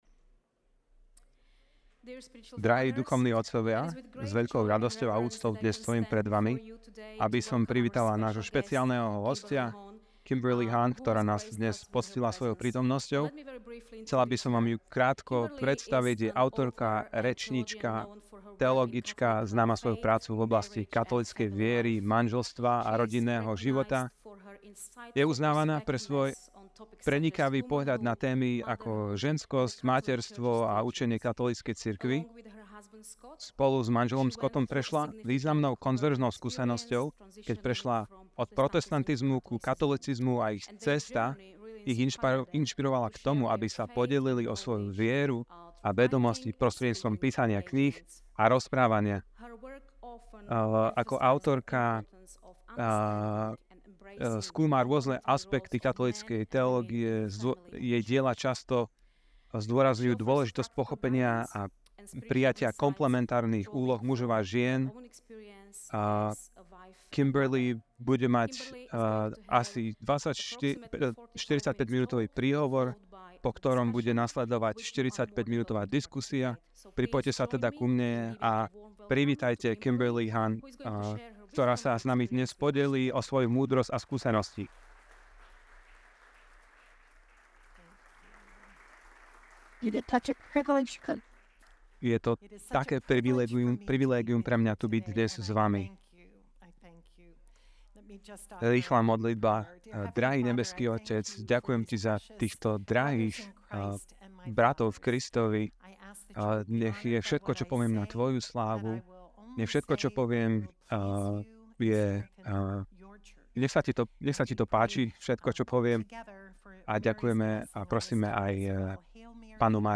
Kňazská konferencia
Prednáška